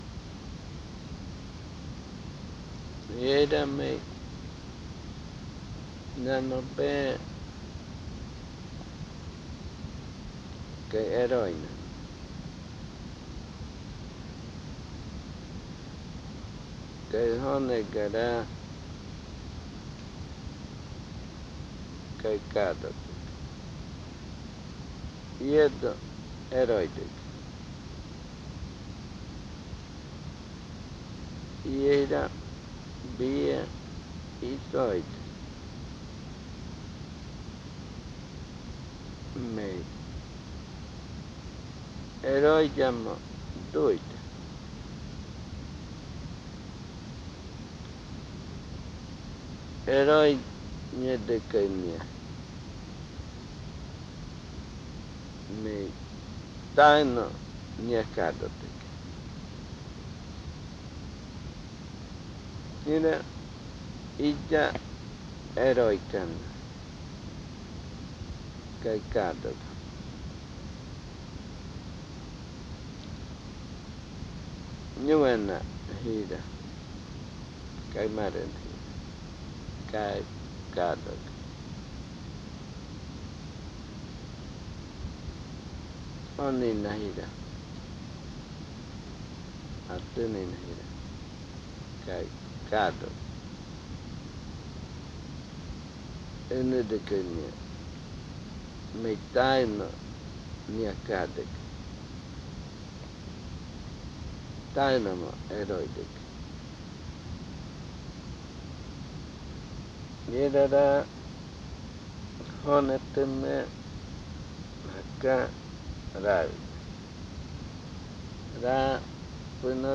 Adofikɨ (Cordillera), río Igaraparaná, Amazonas